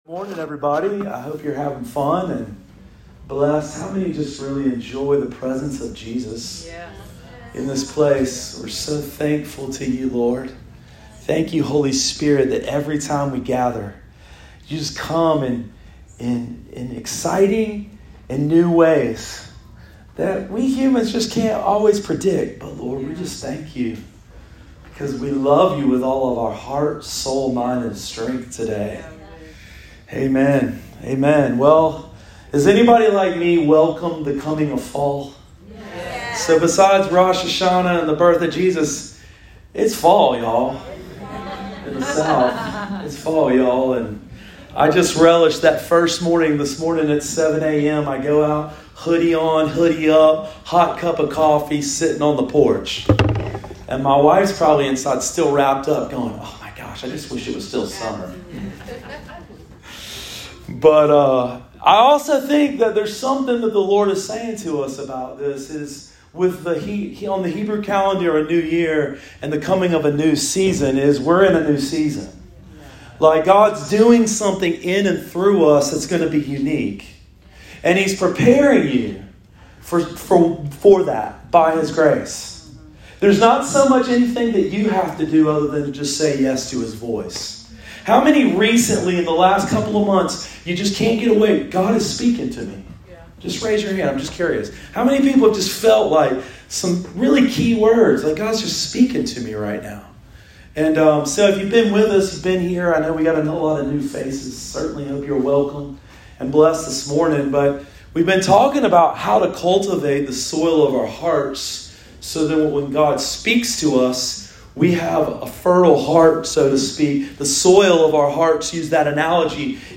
Sermon of the Week: 9/25/22 – RiverLife Fellowship Church